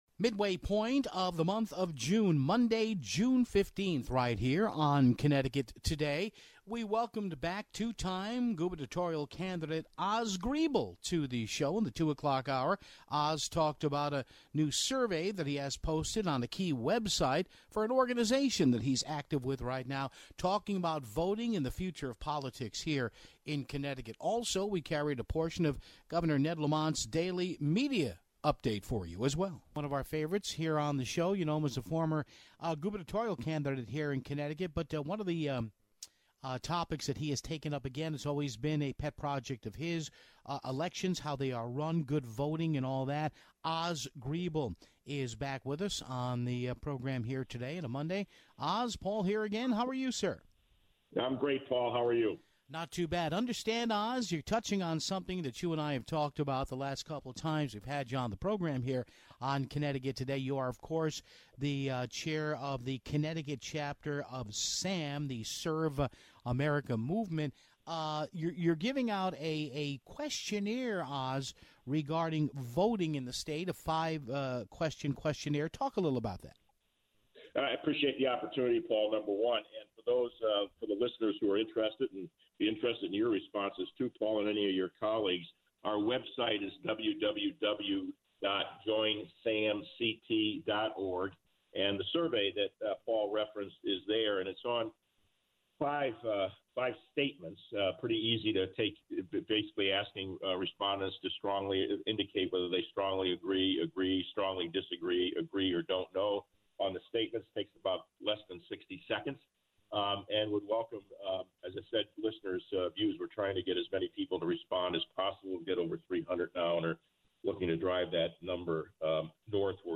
Governor Ned Lamont than gave his daily press briefing on COVID-19 and Racial Injustice